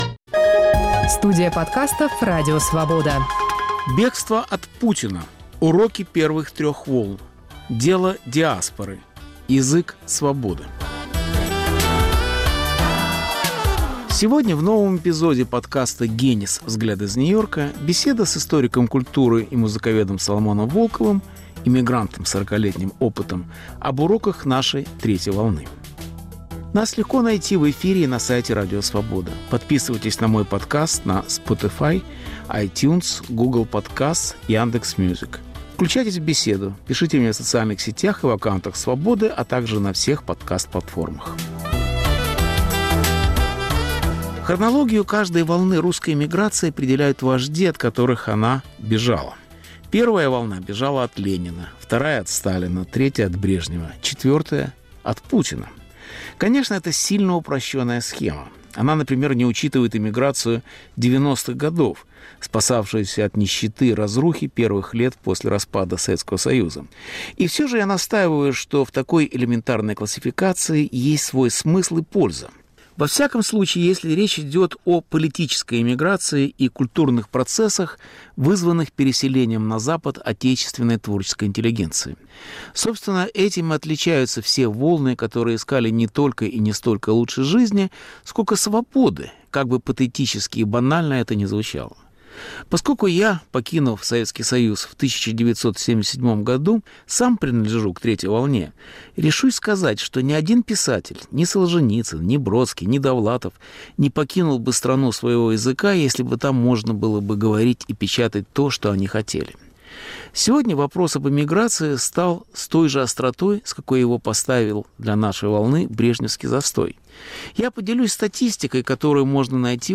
Беседа с Соломоном Волковым об уроках эмиграции в диаспоре и метрополии